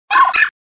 contributions)Televersement cris 4G.